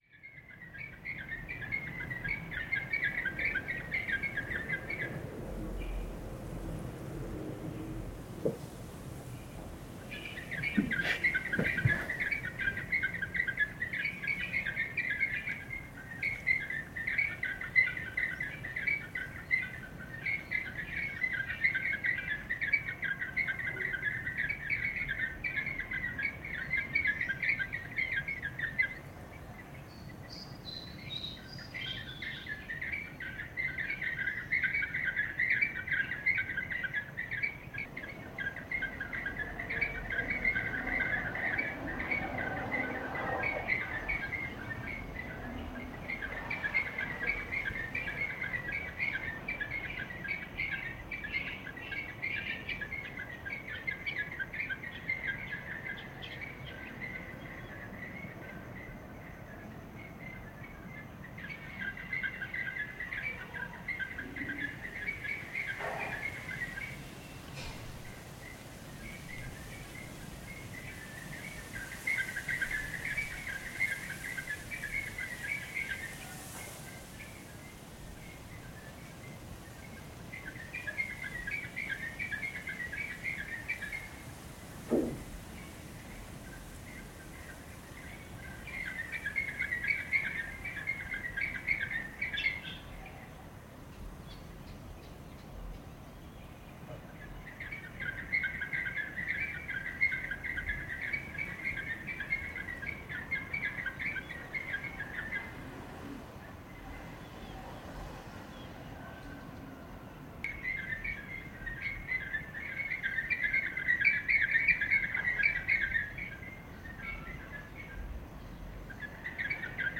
Birds on a mango tree, Phnom Penh
Birds singing in a mango tree, Phnom Penh, Cambodia, September 2016.